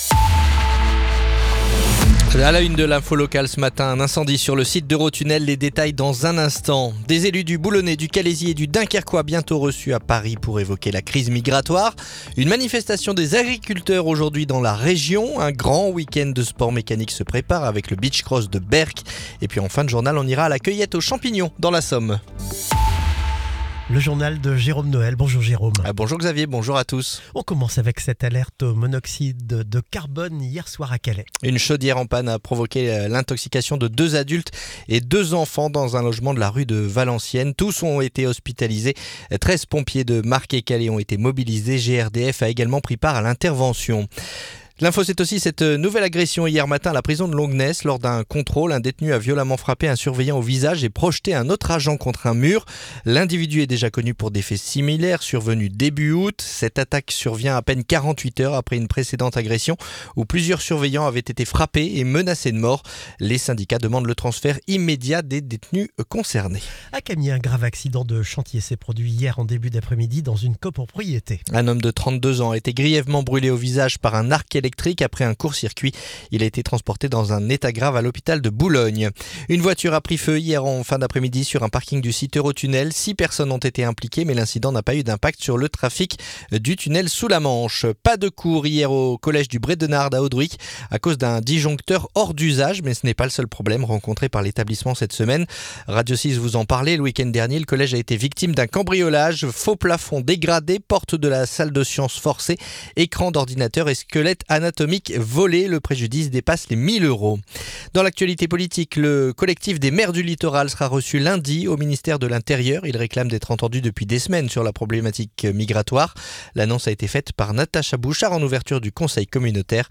Le journal du vendredi 26 septembre